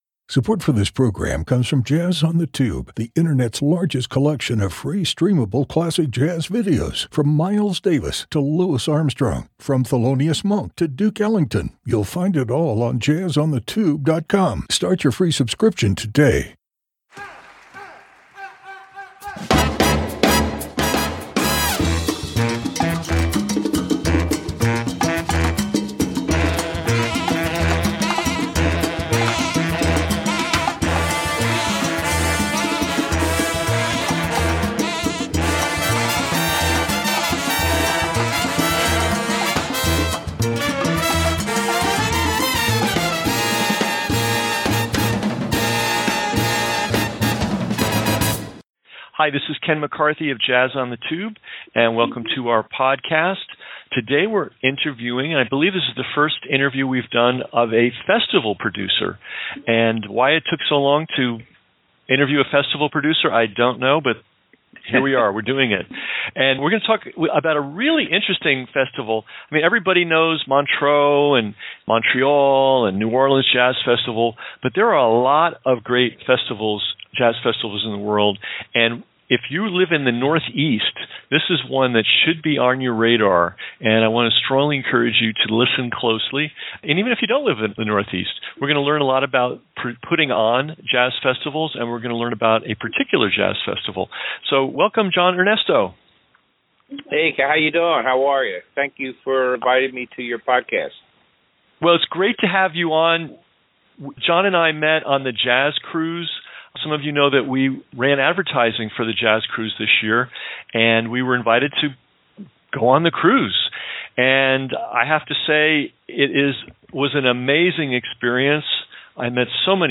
Blog, Jazz on the Tube Interview, Podcasts, Producer-Presenters